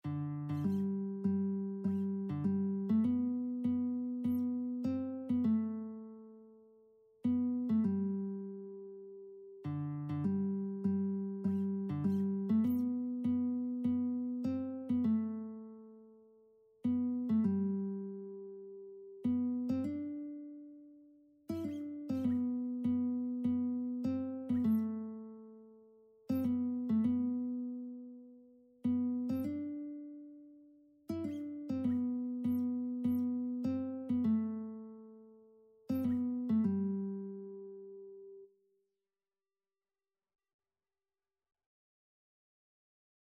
Christian
4/4 (View more 4/4 Music)
Classical (View more Classical Lead Sheets Music)